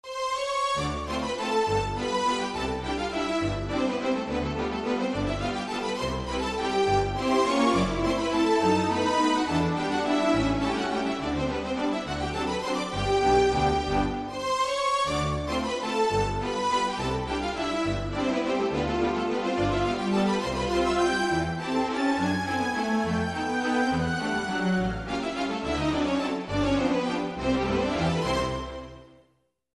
Strings (Yamaha Clavinova).mp3